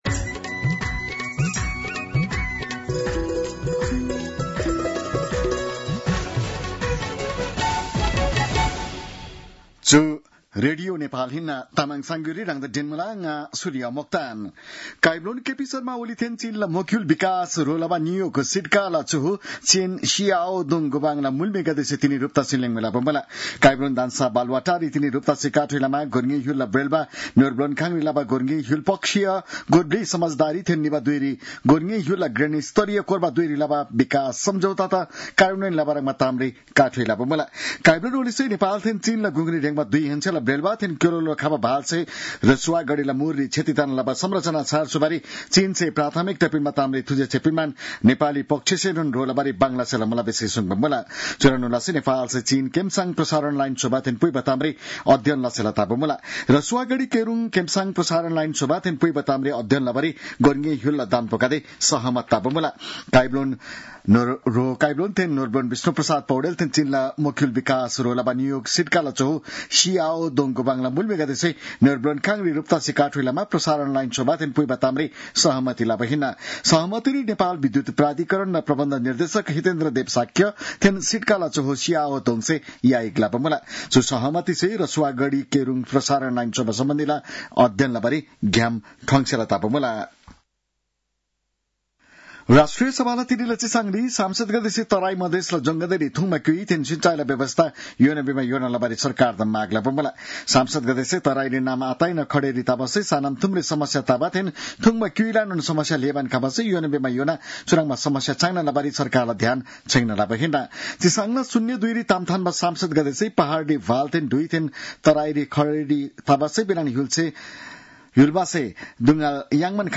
An online outlet of Nepal's national radio broadcaster
तामाङ भाषाको समाचार : ६ साउन , २०८२